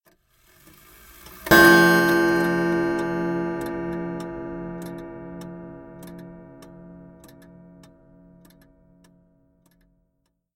Loud Chime
Tags: clock